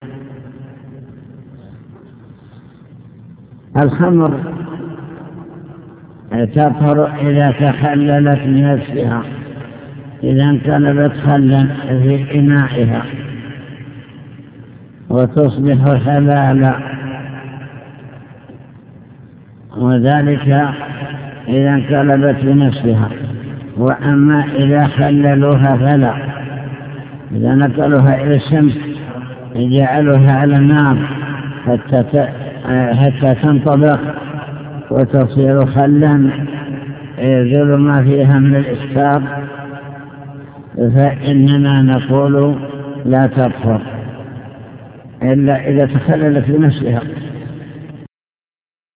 المكتبة الصوتية  تسجيلات - كتب  شرح كتاب دليل الطالب لنيل المطالب كتاب الطهارة باب إزالة النجاسة